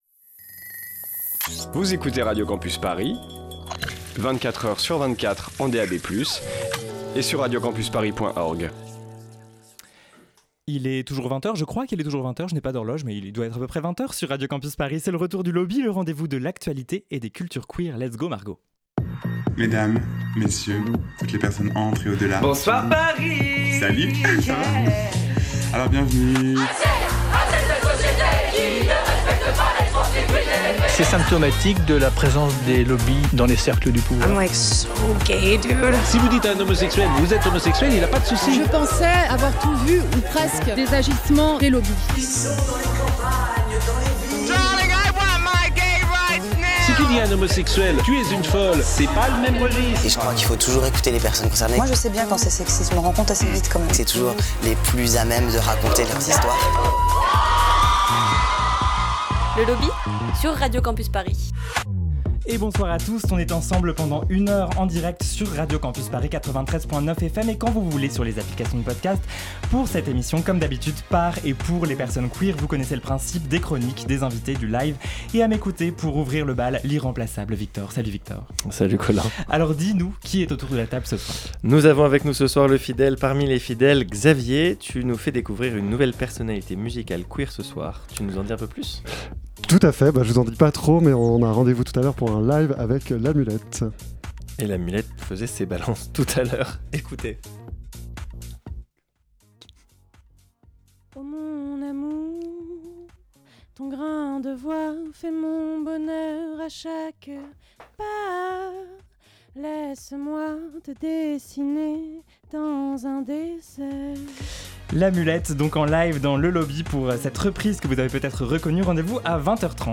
Partager Type Magazine Société mardi 26 mars 2024 Lire Pause Télécharger C'est le retour de notre émission mensuelle !